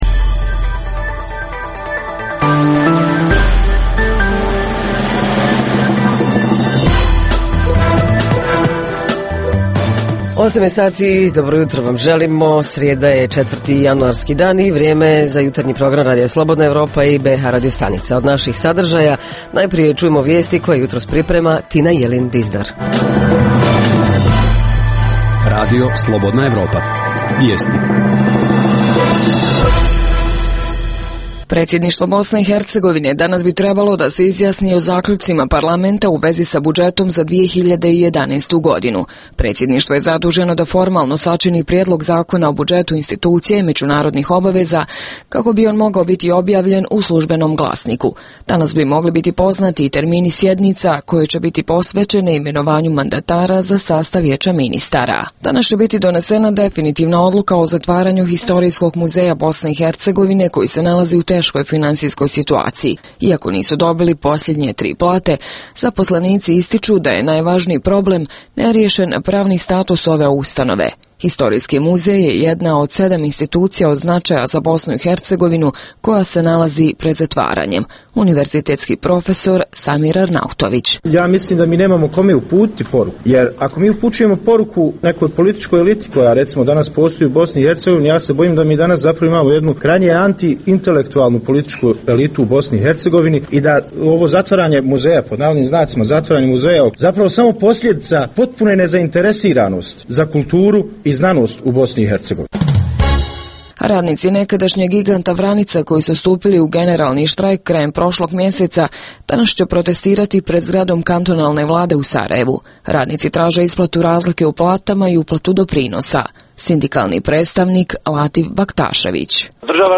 Jutarnji program za BiH koji se emituje uživo. I ovog jutra ostajemo u prazničnom raspoloženju - reporteri iz cijele BiH javljaju o zanimljivostima iz njihovih sredina.